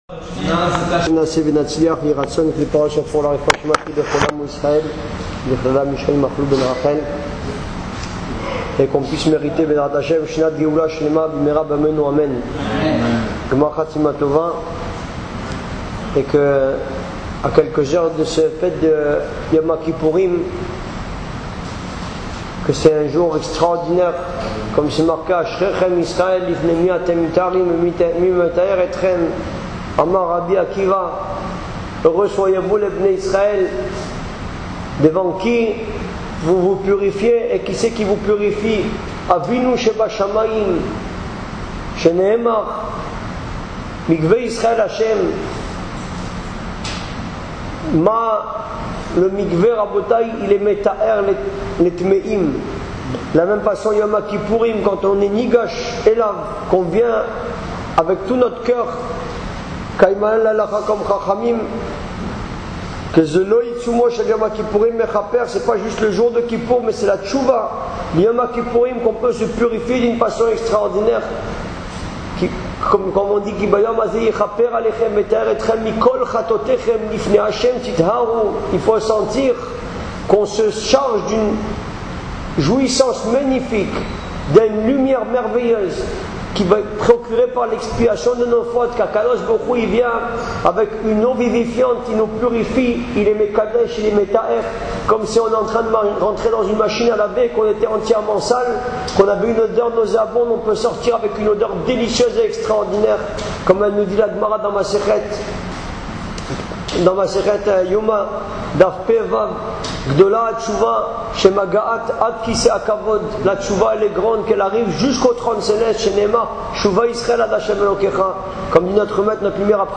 Cours